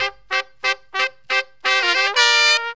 HORN RIFF 8.wav